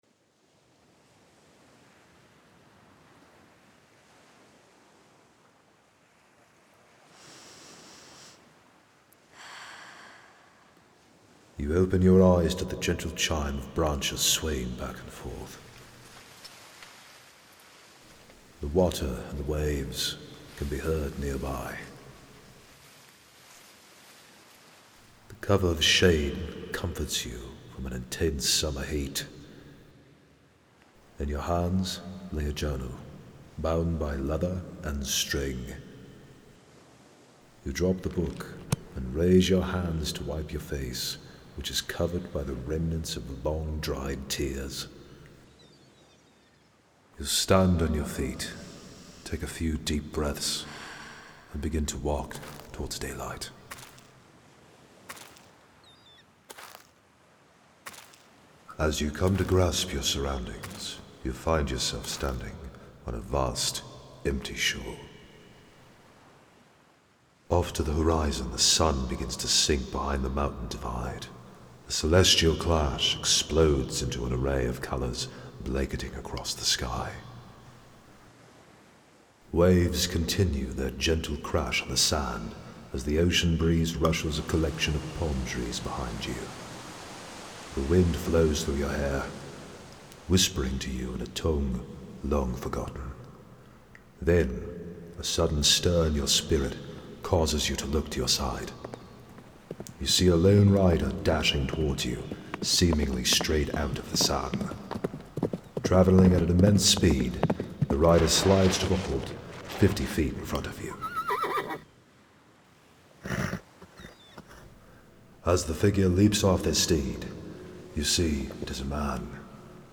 Character Voice Demos